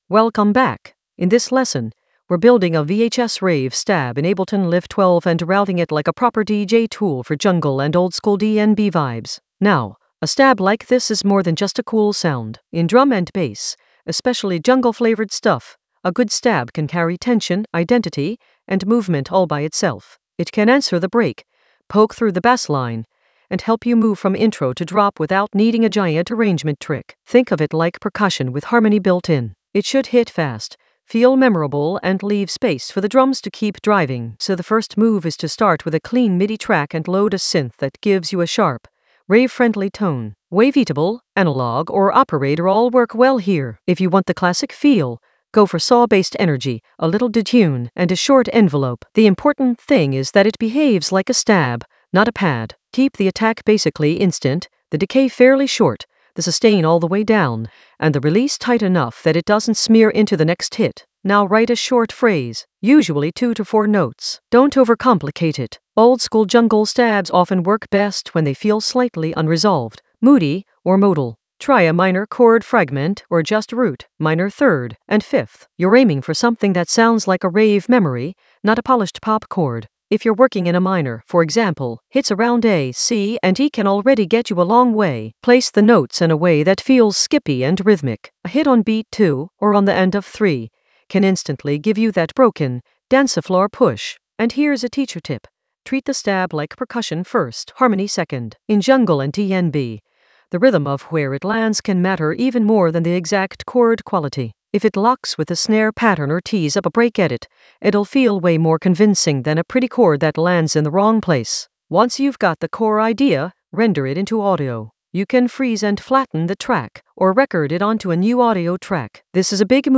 An AI-generated intermediate Ableton lesson focused on Route a VHS-rave stab in Ableton Live 12 for jungle oldskool DnB vibes in the DJ Tools area of drum and bass production.
Narrated lesson audio
The voice track includes the tutorial plus extra teacher commentary.